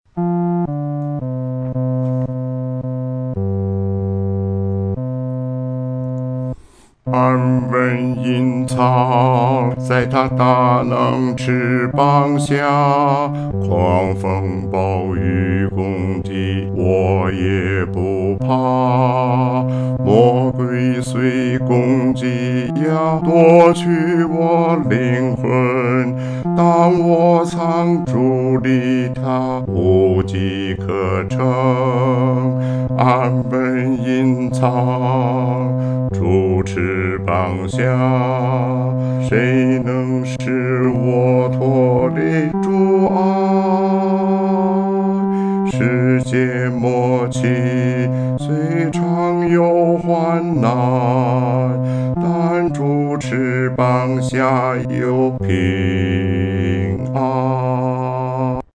独唱（第四声）
主翅膀下-独唱（第四声）.mp3